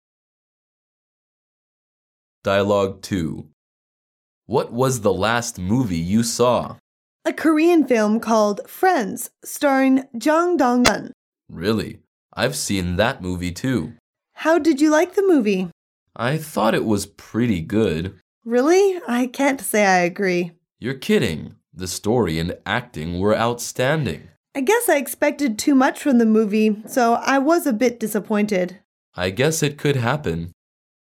Dialoug 2